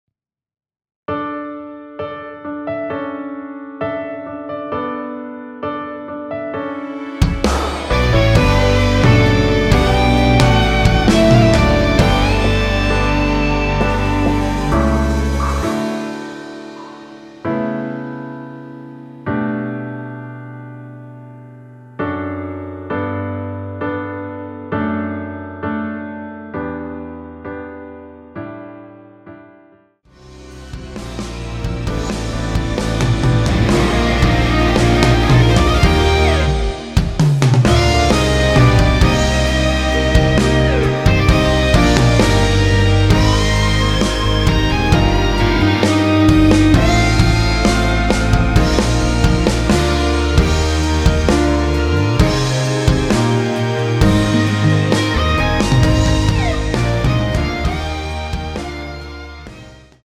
원키에서(+1)올린 MR입니다.
D
◈ 곡명 옆 (-1)은 반음 내림, (+1)은 반음 올림 입니다.
앞부분30초, 뒷부분30초씩 편집해서 올려 드리고 있습니다.
중간에 음이 끈어지고 다시 나오는 이유는